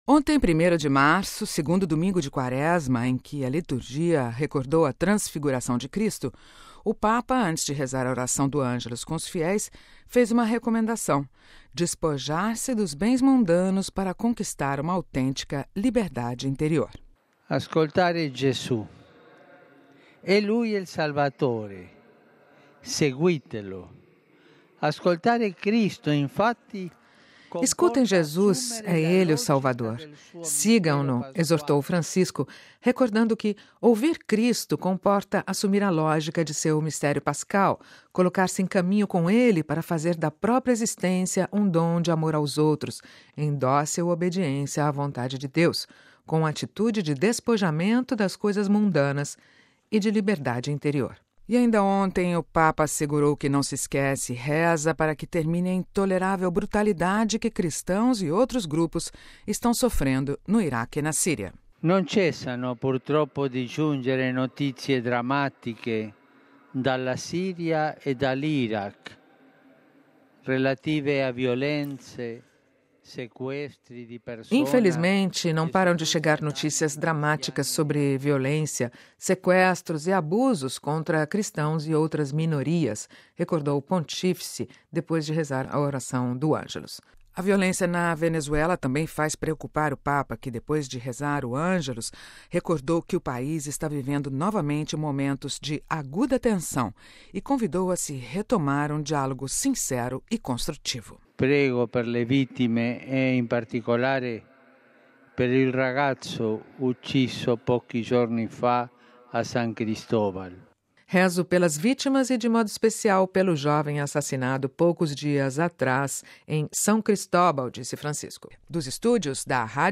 Boletim da Rádio Vaticano